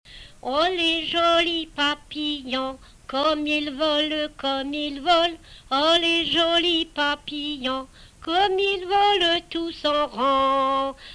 Chanson
Emplacement Miquelon